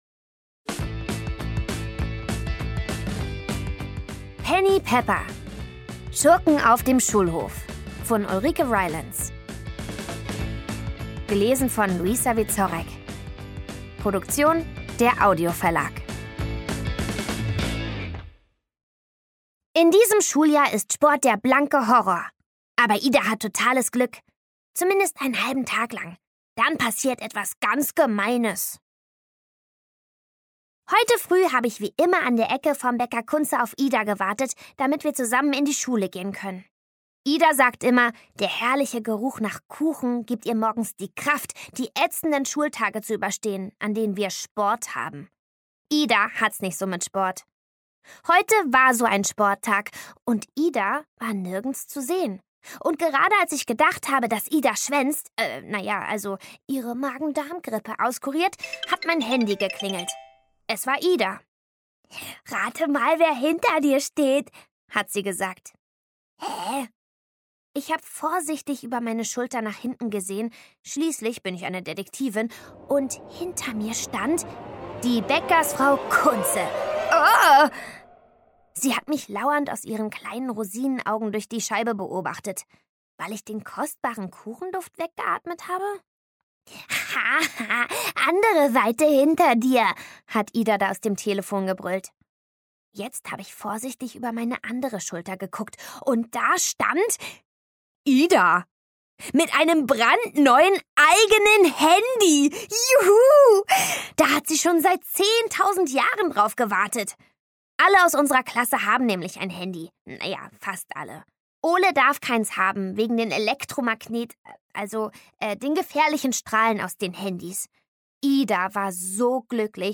Penny Pepper – Teil 8: Schurken auf dem Schulhof Szenische Lesung mit Musik